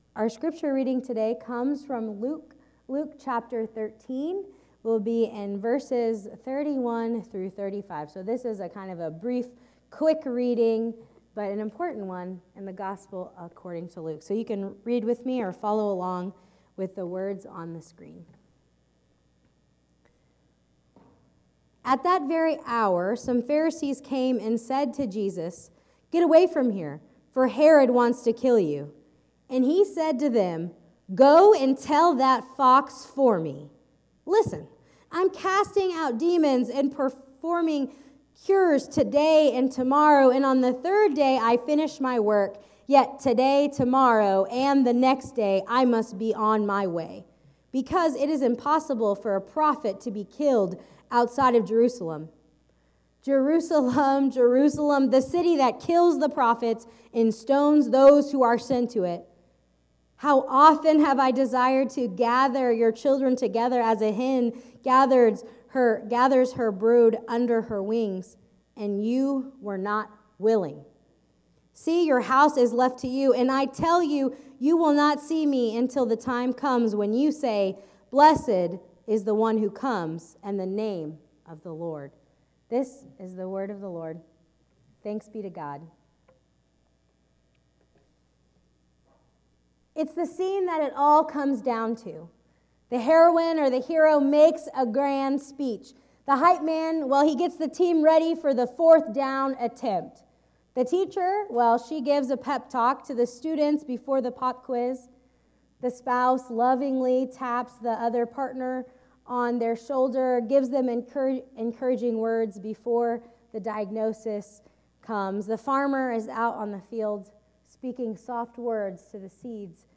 Enjoy your effective and faithful preaching.